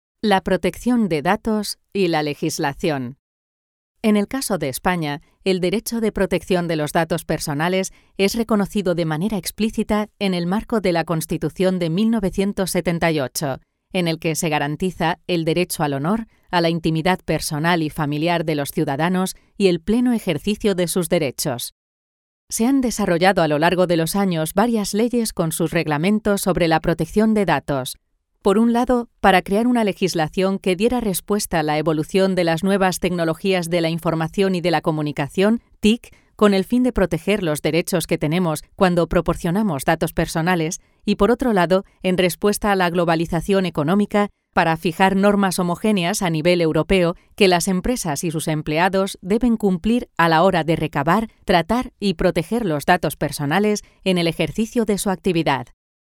PERFIL VOZ: Rotunda, cálida, clara, carismática, enérgica, vibrante, entusiasta, sofisticada, sensual, versátil, convincente, épica, profunda, elegante.
Sprechprobe: eLearning (Muttersprache):
I have a castillian, native Spanish accent.
demo_elearning_rgpd.mp3